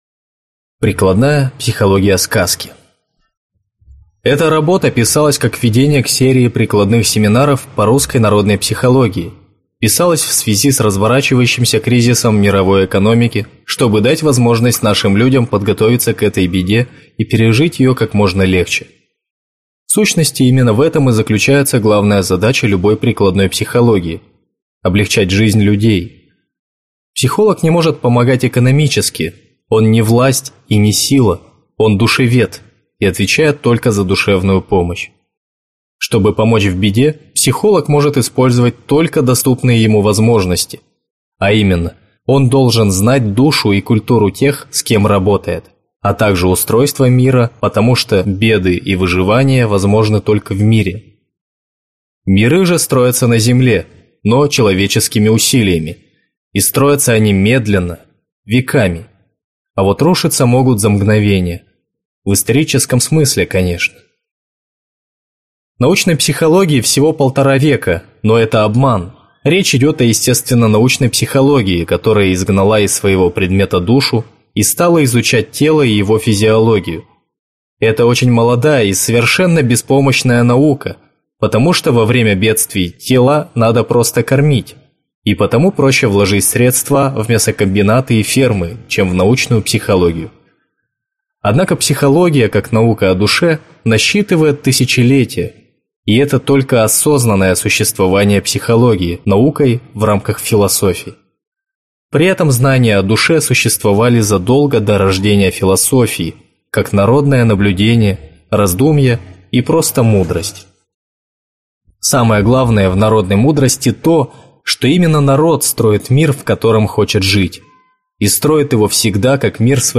Аудиокнига Прикладная психология сказки | Библиотека аудиокниг